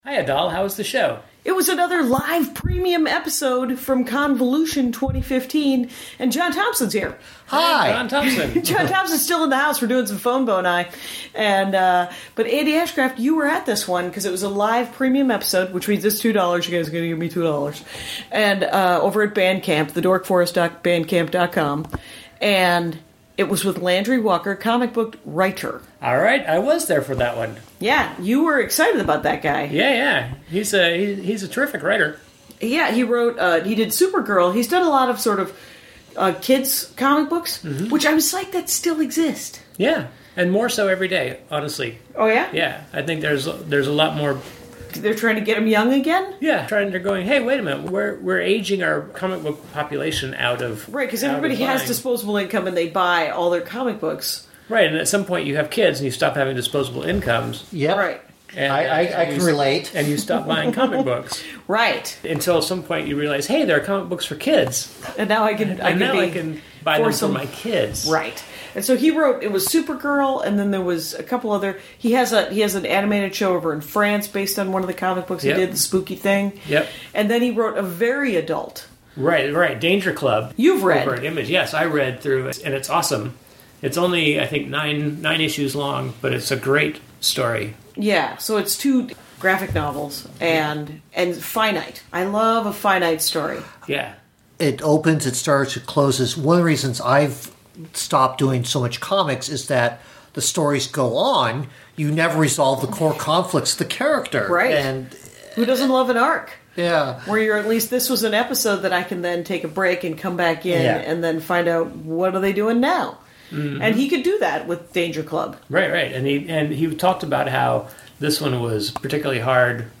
This is a PREMIUM EP.. recorded live.